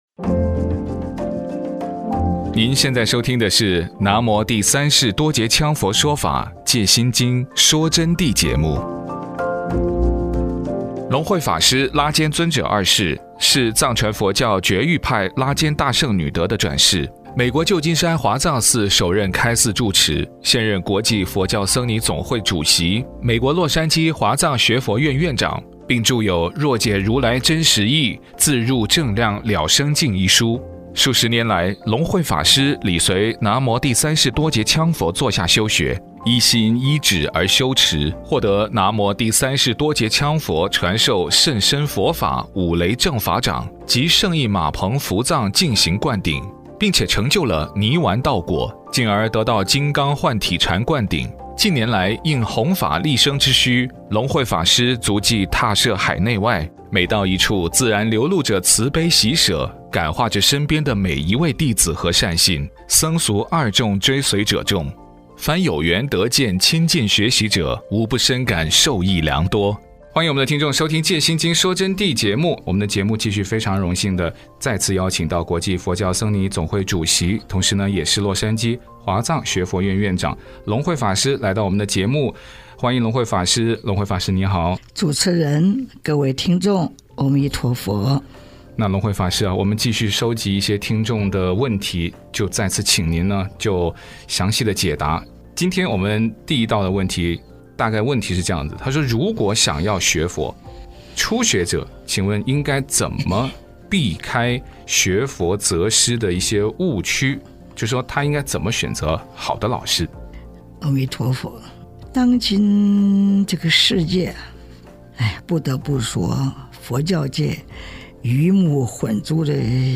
佛弟子访谈（六十八至七十三）